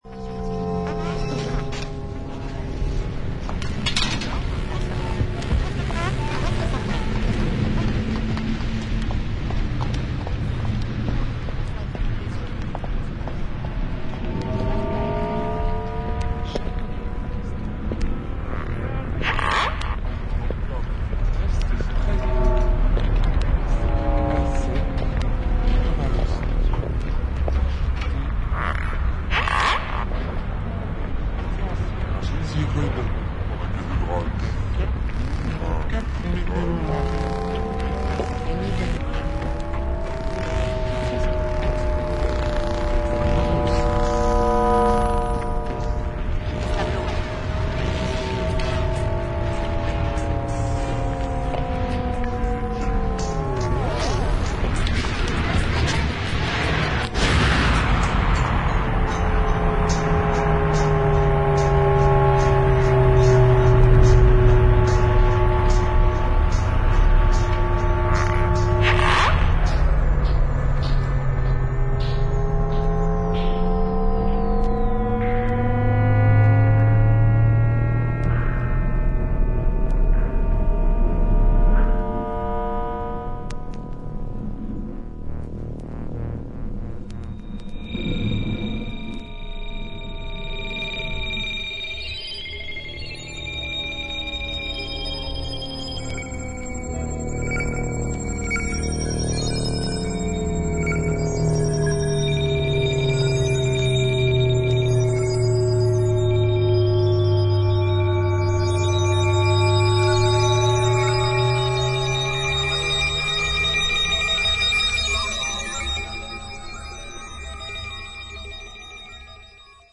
シンプルな編成ながら緻密で、まるで生きもののような深みを感じるエレクトロニクスの音像が堪能できます。